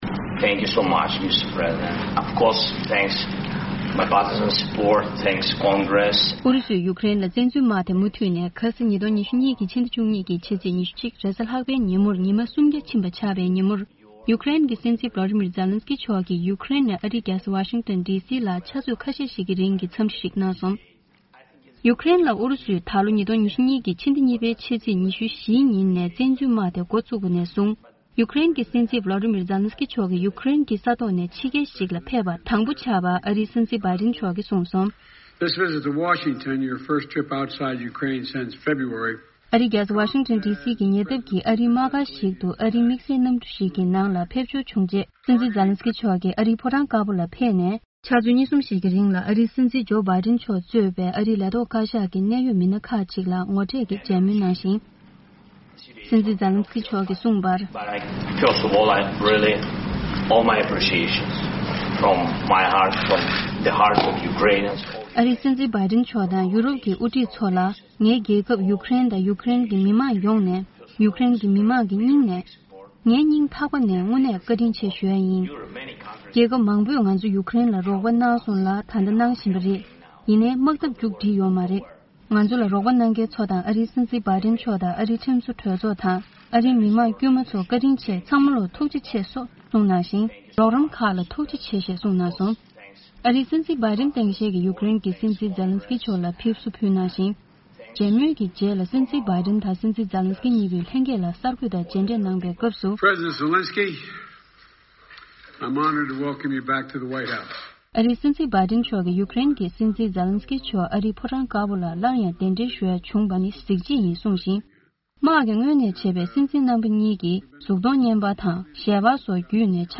སྙན་སྒྲོན་ཞུ་ཡི་རེད།